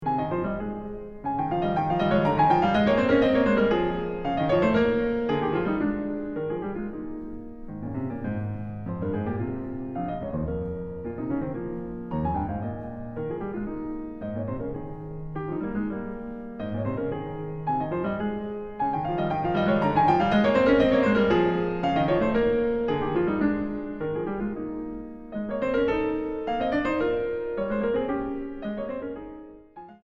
Música mexicana para piano de los siglos XX y XXI.
piano